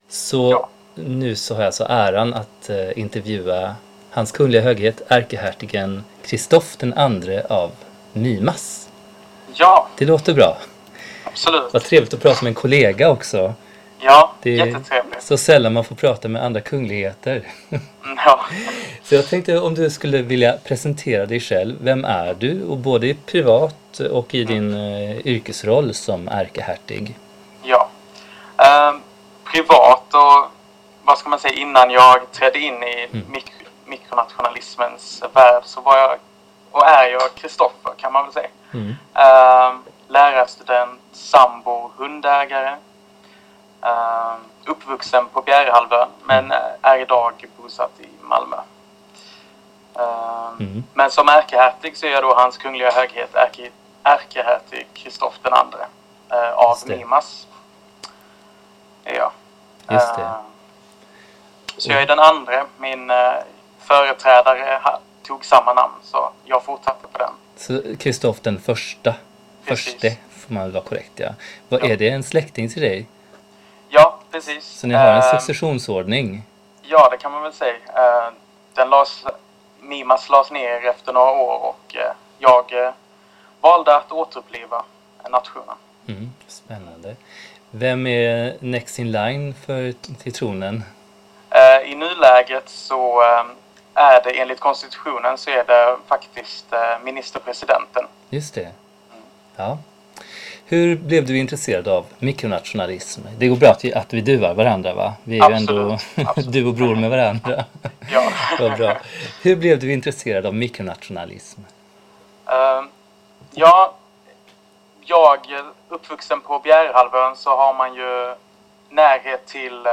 INTERVJU-MIMAS.mp3